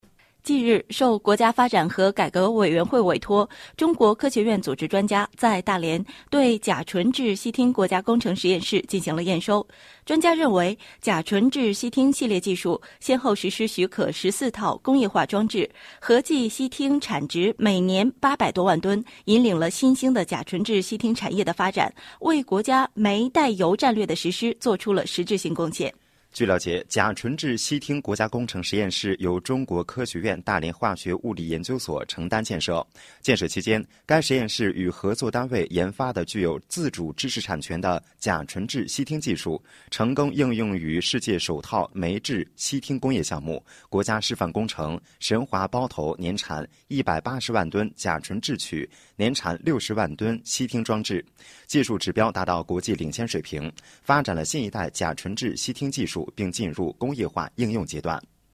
来源：中央人民广播电台 经济广播 《中央媒体看大连》节目